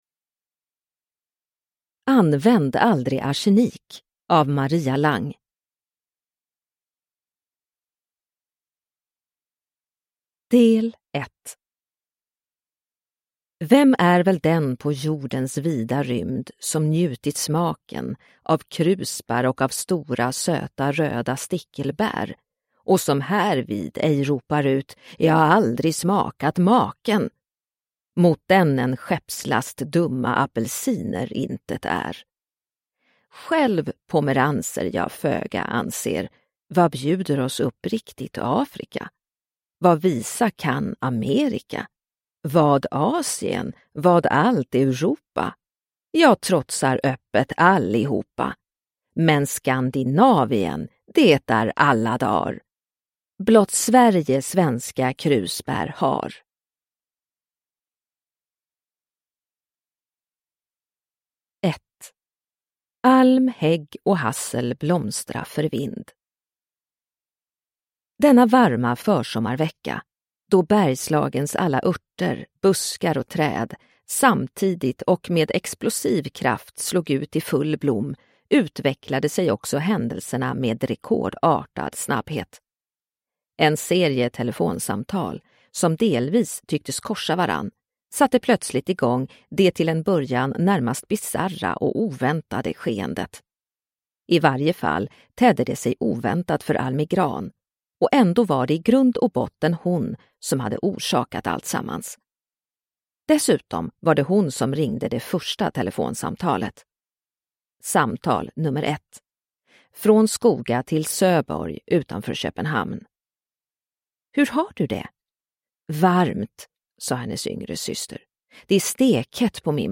Använd aldrig arsenik – Ljudbok – Laddas ner